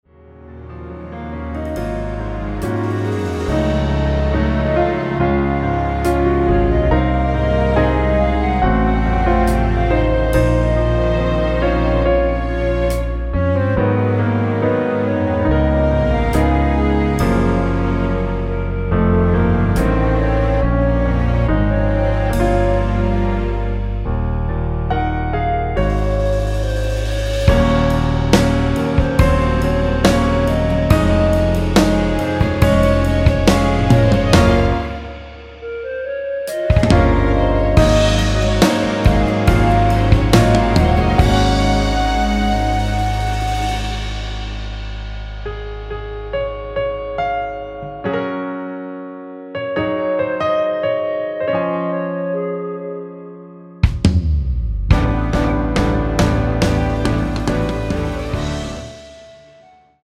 원키에서(-6)내린 멜로디 포함된(1절+후렴) 진행되게 편곡한 MR입니다.
1절 “정말 고마워” 다음 후렴부분인 “밤에 울다 잠이 깼을 때”로 진행 되게 편곡하였습니다.
앞부분30초, 뒷부분30초씩 편집해서 올려 드리고 있습니다.
중간에 음이 끈어지고 다시 나오는 이유는